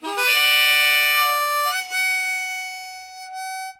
混合型标准口琴（所有调）点击率+持续率 " A口琴1 - 声音 - 淘声网 - 免费音效素材资源|视频游戏配乐下载
口琴用我的AKG C214在我的楼梯上录制的单声道，以获得那种橡木的音色